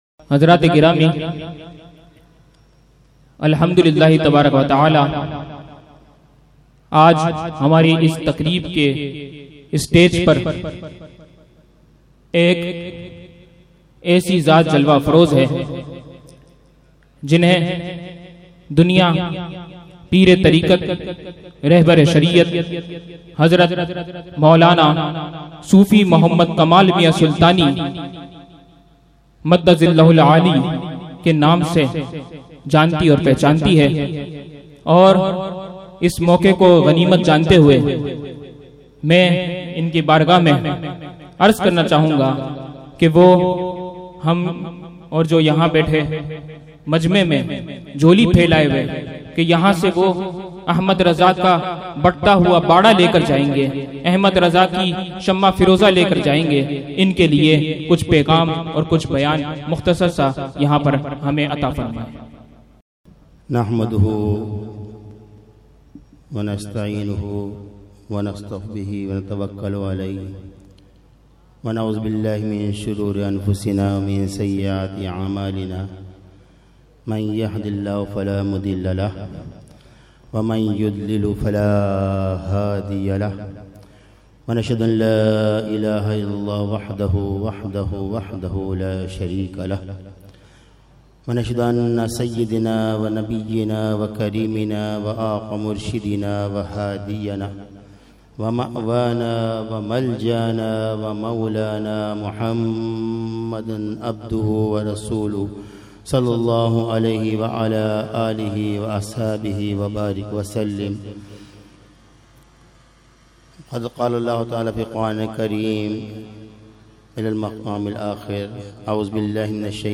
خطاب